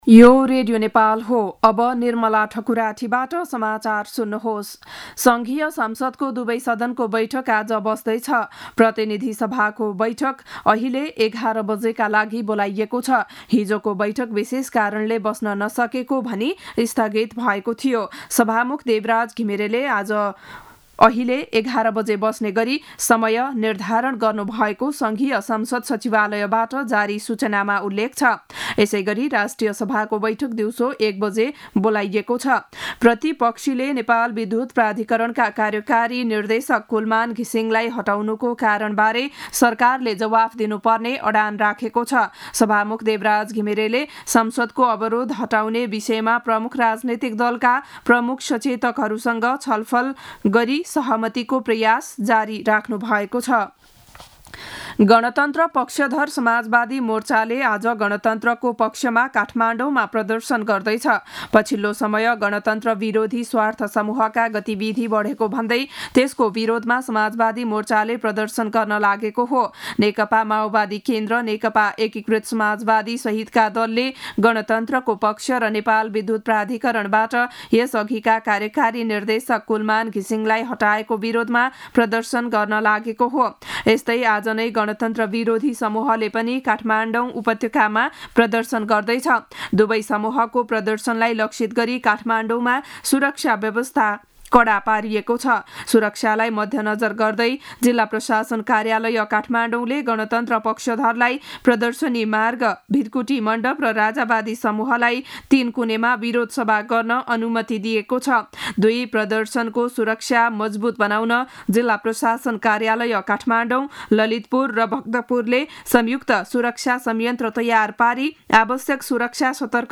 बिहान ११ बजेको नेपाली समाचार : १५ चैत , २०८१